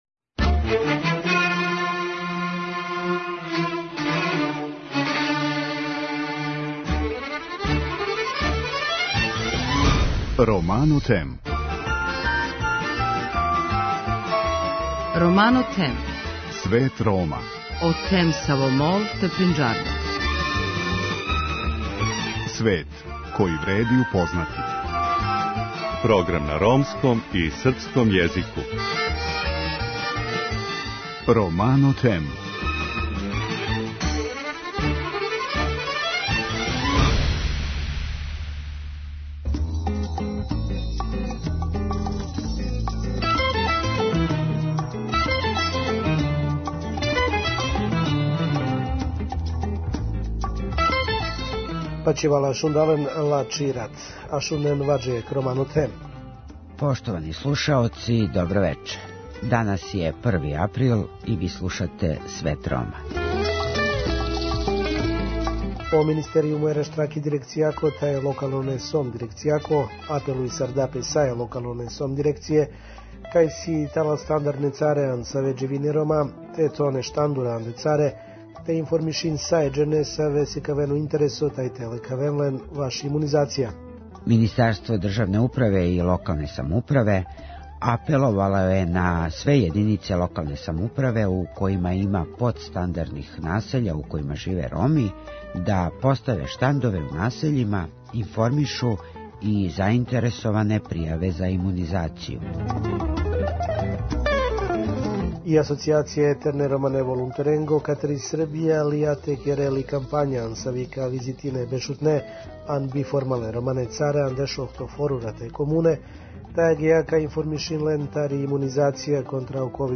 Ромски активисти изражавају забринутост да се процесом асимилације Роми налазе на путу да изгубе свој језик. О томе, у другом делу емисије, говоре ромски активисти на територији Косова и Метохије.